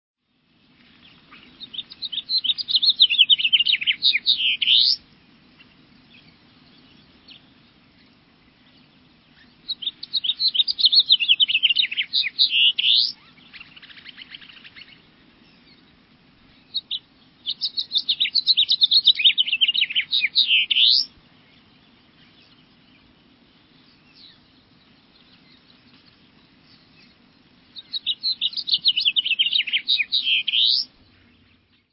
House Finch
Bird Sound
Song a hoarse warble that goes up and down rapidly. Call note a sharp "cheep."
HouseFinch.mp3